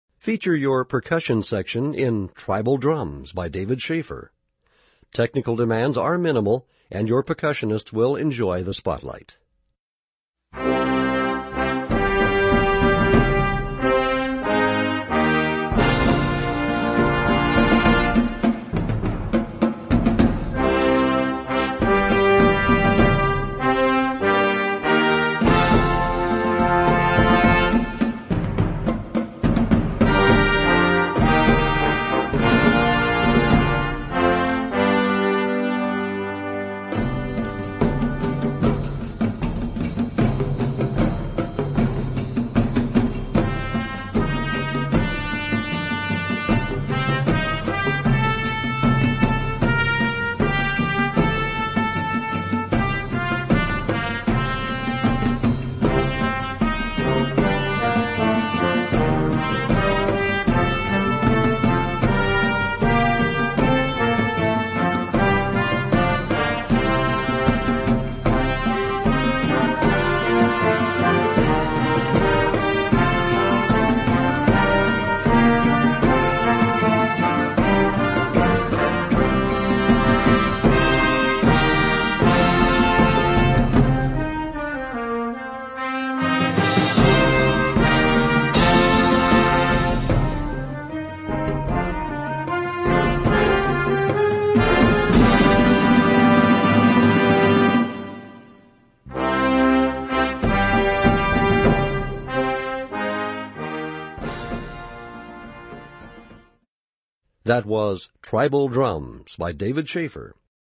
Voicing: Percussion Section w/ Band